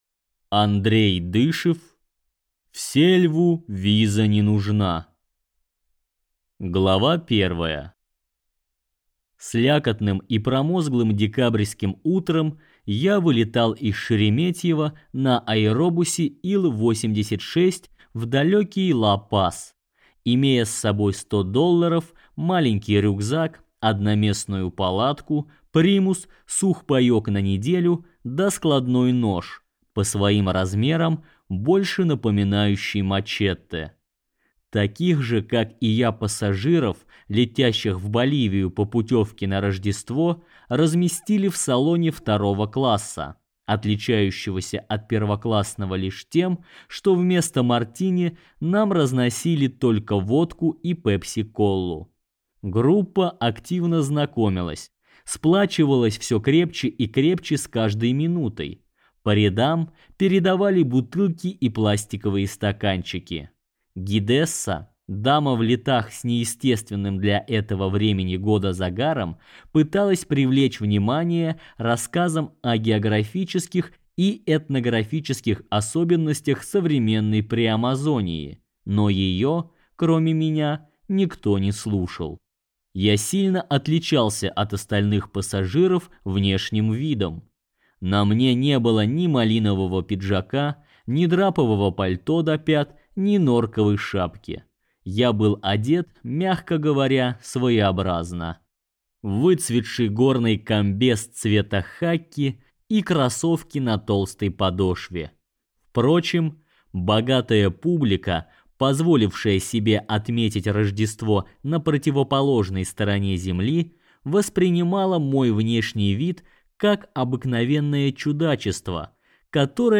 Аудиокнига В сельву виза не нужна | Библиотека аудиокниг